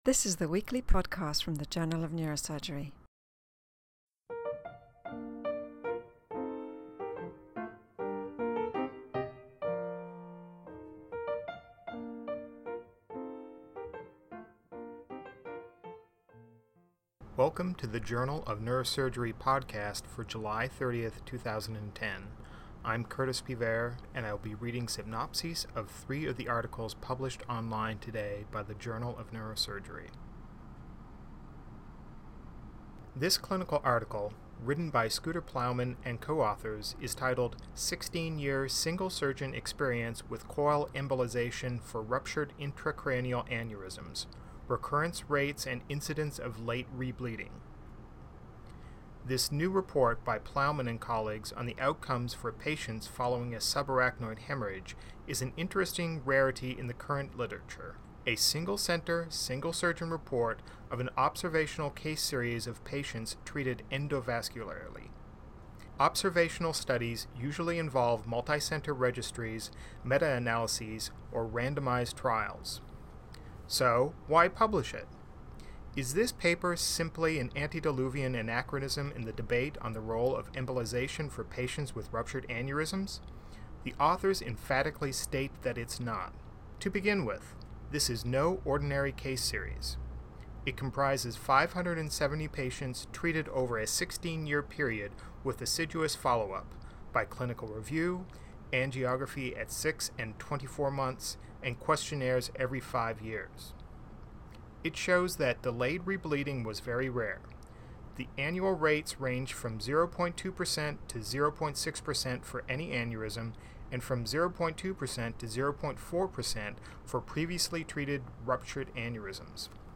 reads synopses of Journal of Neurosurgery articles published online on July 30, 2010.